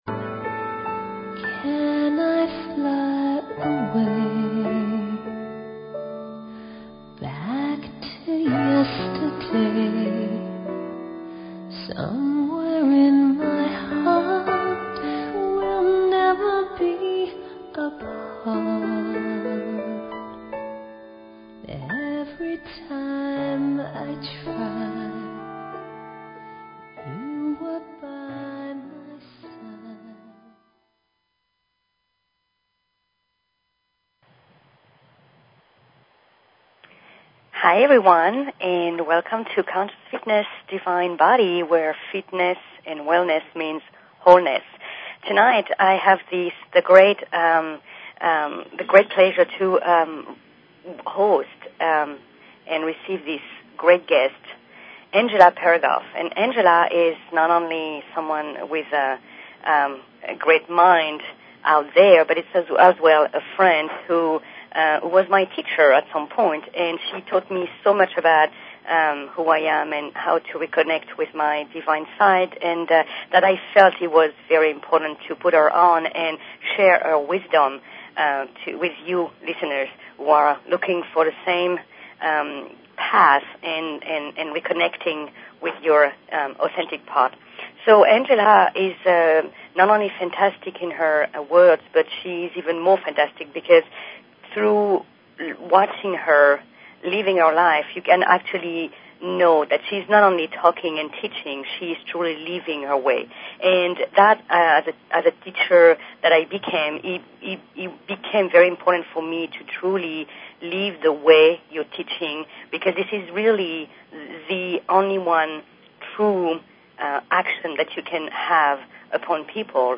Talk Show Episode, Audio Podcast, Conscious_Fitness and Courtesy of BBS Radio on , show guests , about , categorized as